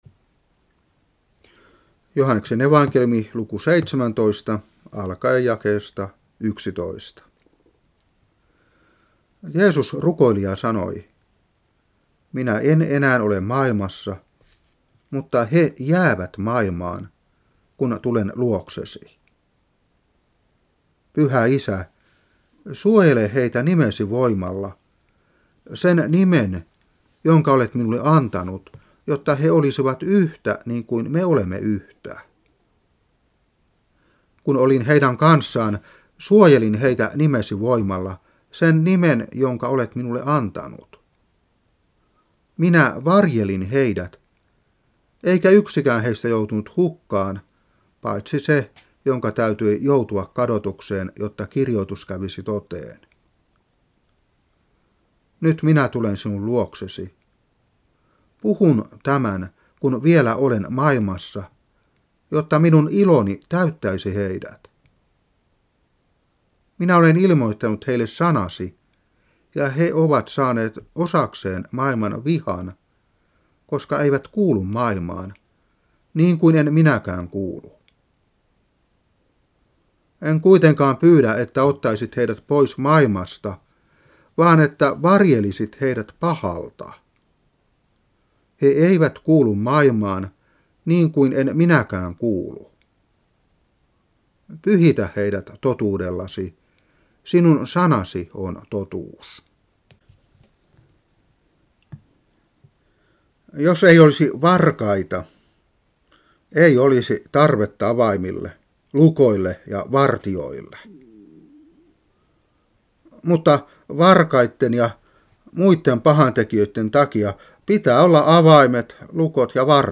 Saarna 2008-4. Joh.17:11-17.